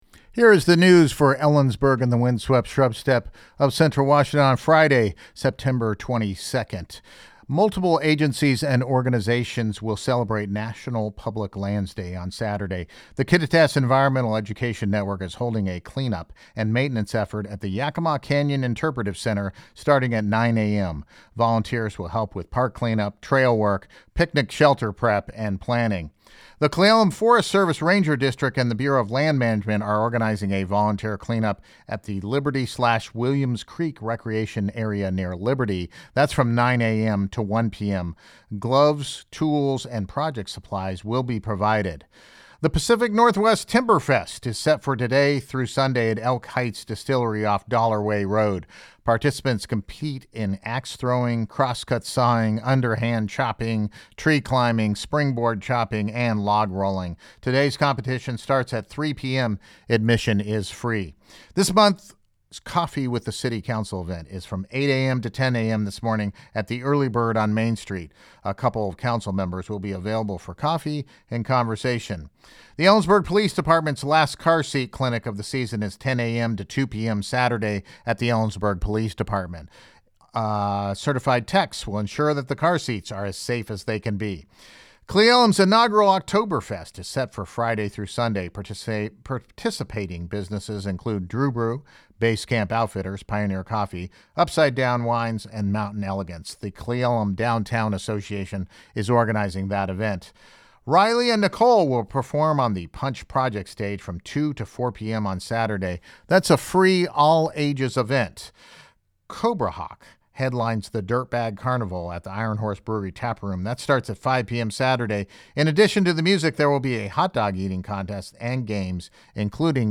Photo courtesy of U.S. Forest Service LISTEN TO THE NEWS HERE NEWS Celebrating public lands Saturday Multiple agencies and organizations will celebrate National Public Lands Day on Saturday.